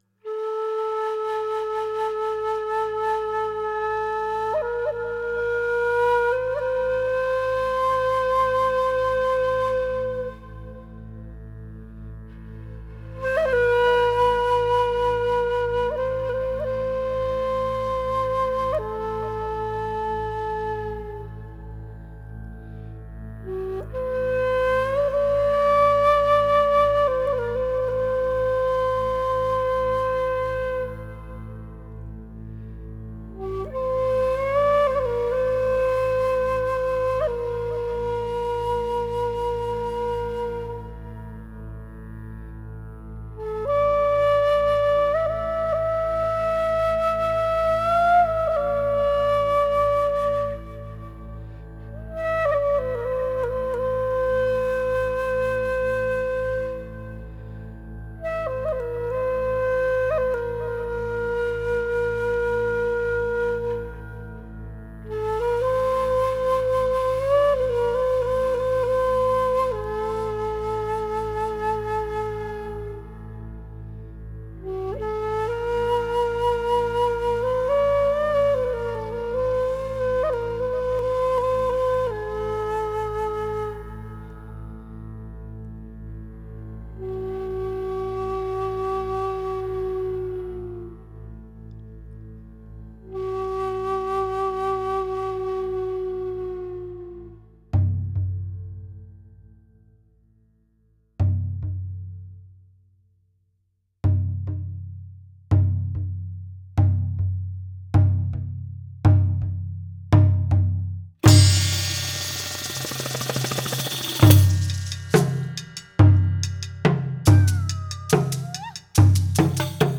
World/Meditative